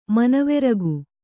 ♪ manaveragu